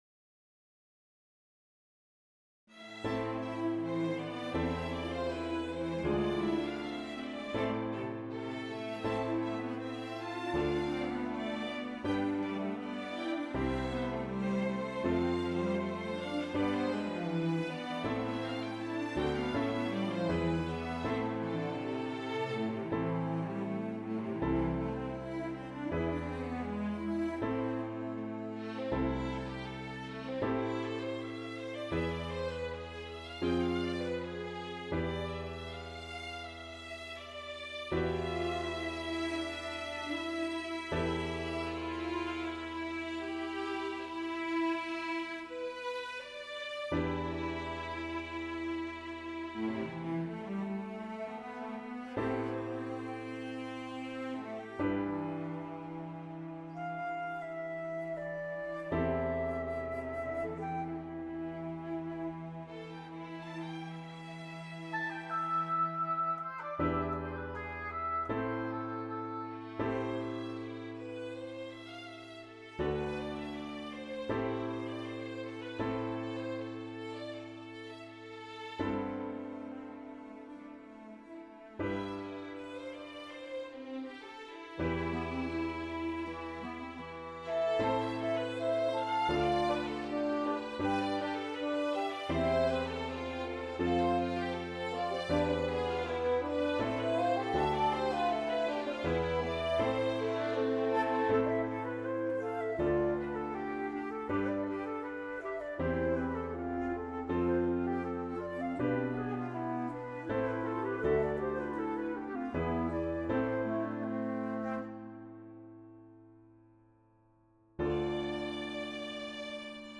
Miniature concerto for violin
Instead they want me to write a miniature concerto for violin and chamber orchestra. I've started sketching it out here; though I'm still at a very early stage.